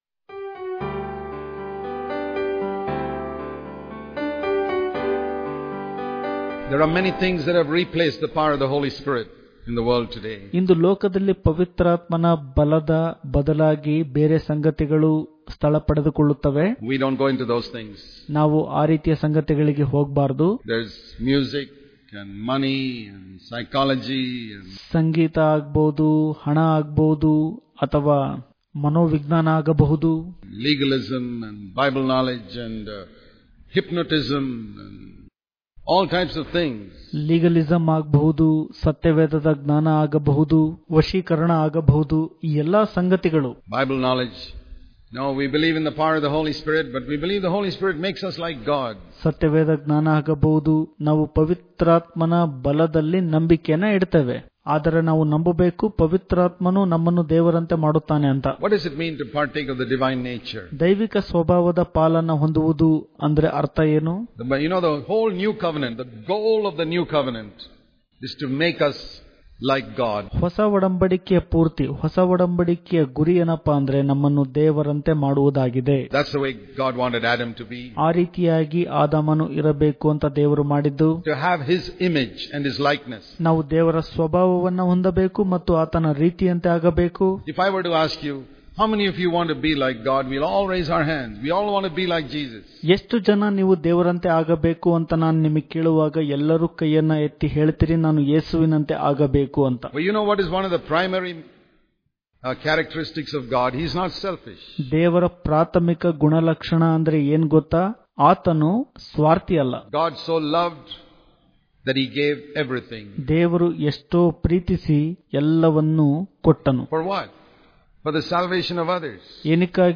September 8 | Kannada Daily Devotion | Jesus Came To Save Us From The Love Of Money And Selfishness Daily Devotions